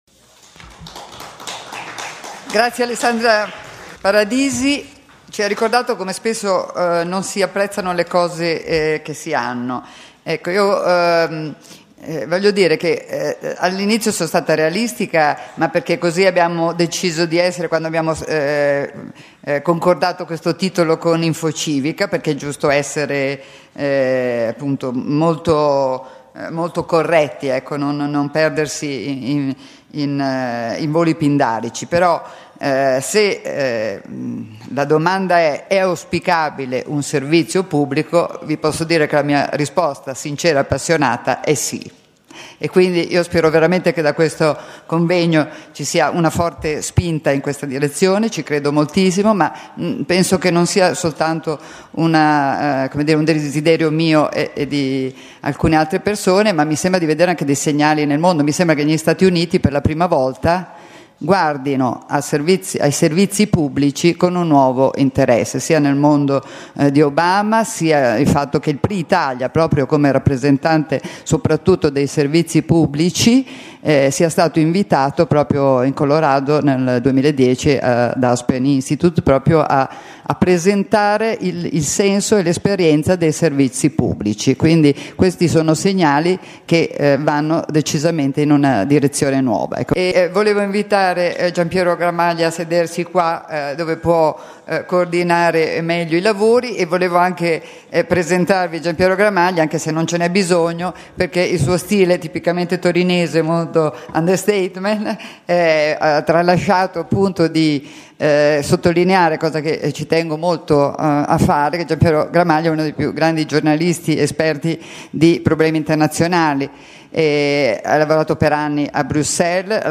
Torino Prix Italia – 24 settembre 2009